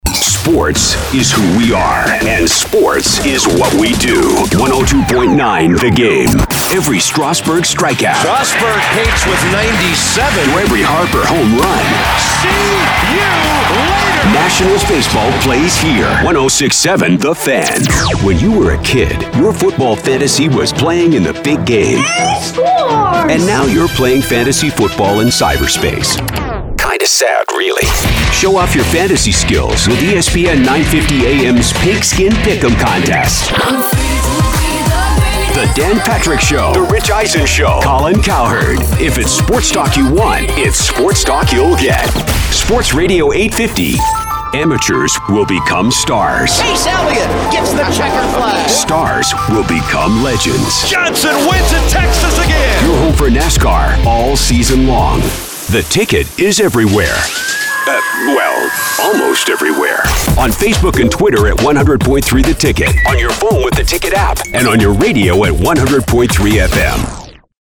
Male
Adult (30-50), Older Sound (50+)
Radio Imaging Demo - Sports
Words that describe my voice are Gravitas, Authority, Mature.
All our voice actors have professional broadcast quality recording studios.